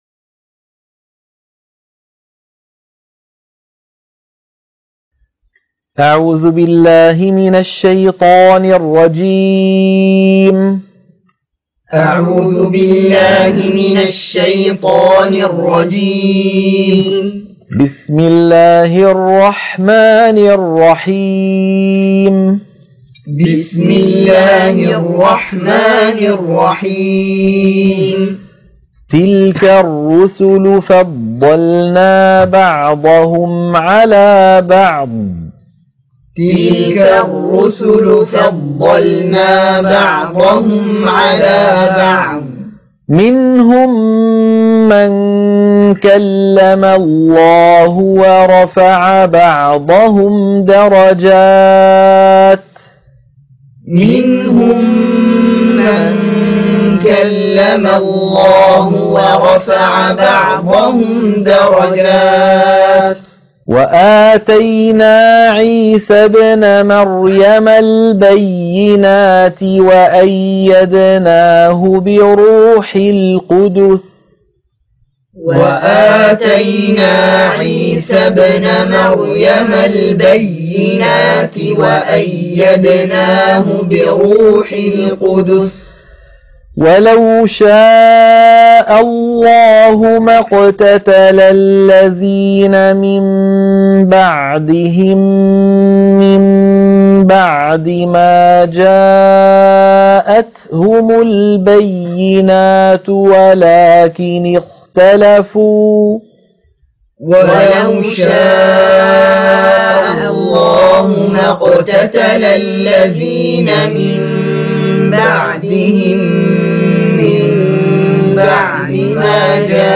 تلقين سورة البقرة - الصفحة 42 التلاوة المنهجية - الشيخ أيمن سويد